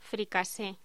Locución: Fricasé
voz